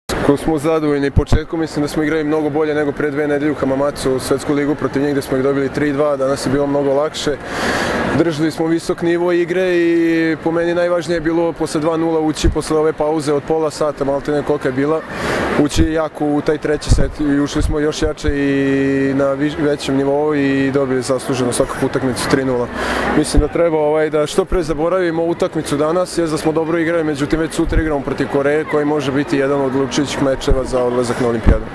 IZJAVA MARKA PODRAŠČANINA